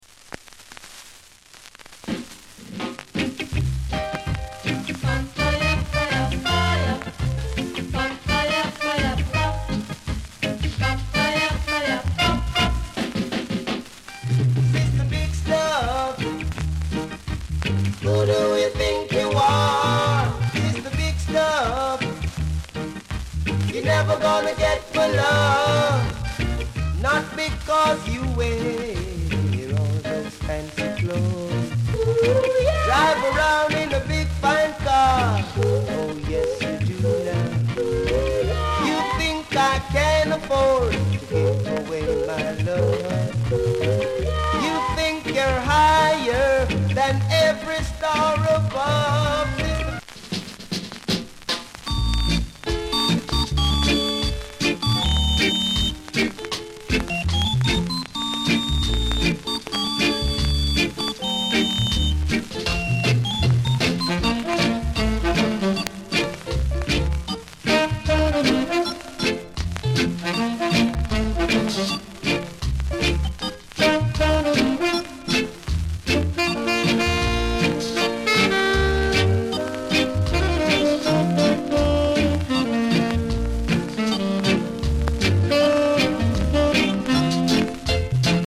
Notes: hissy press